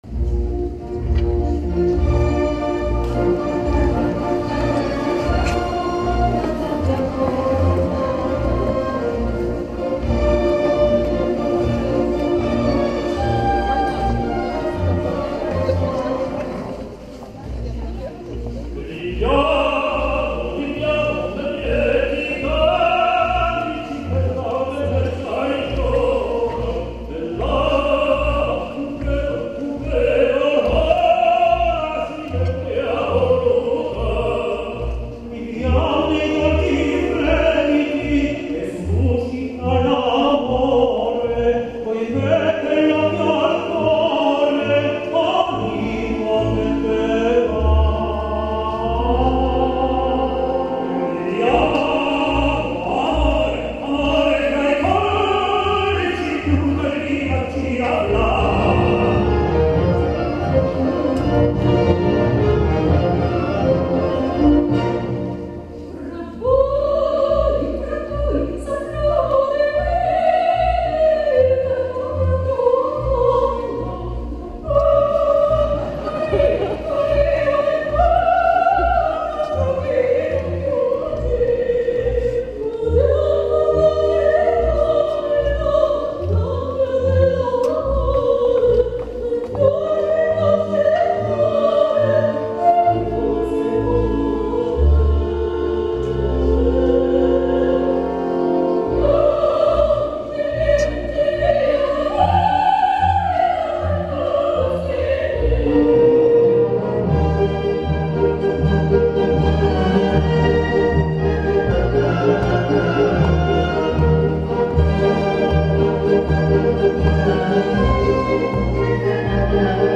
Posłuchaj mnie - utwory wykonane z towarzyszeniem orkiestry